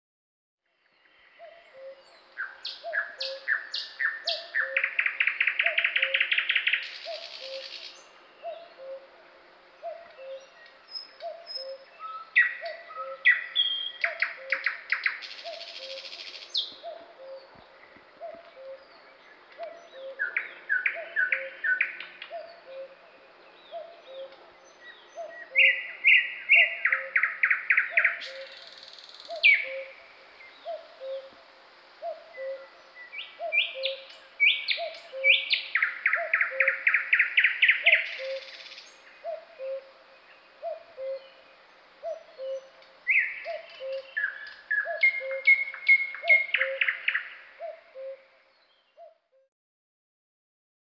Kuuntele: Käki kukkuu latvuston kätköissä